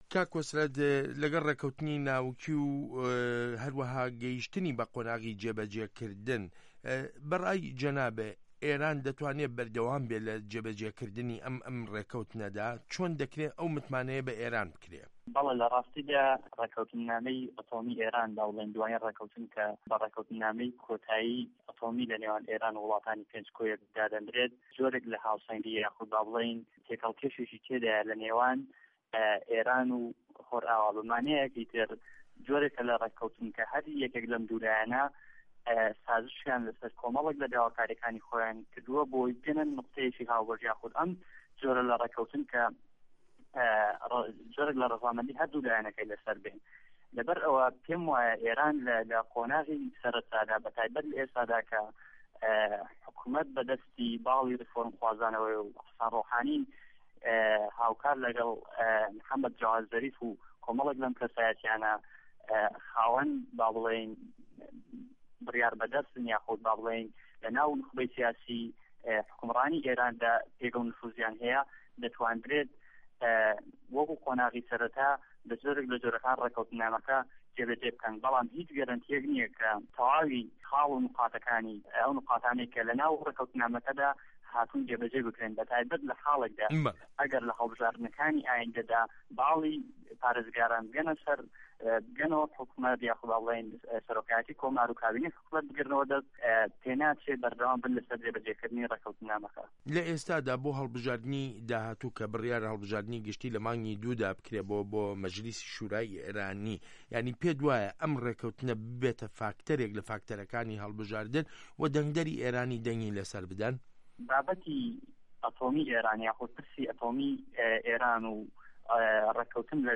زانیاری زیاتر لە دەقی وتووێژەکەدایە: